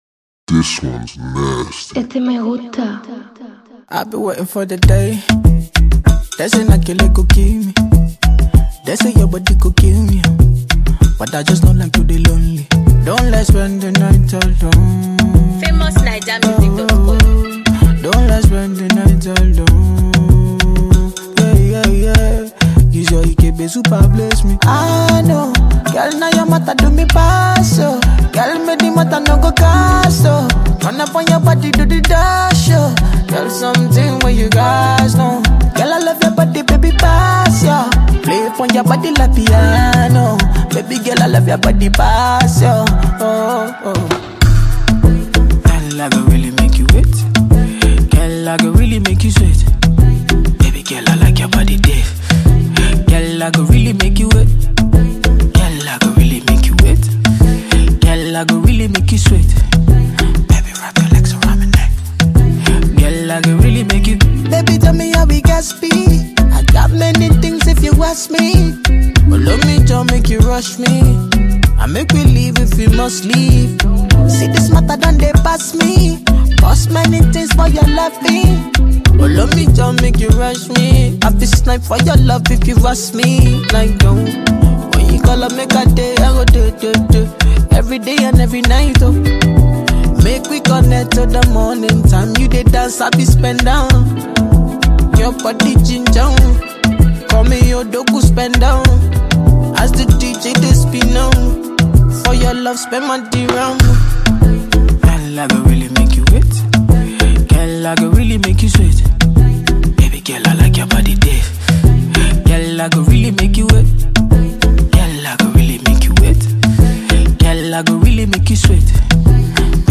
Famous Nigerian vocalist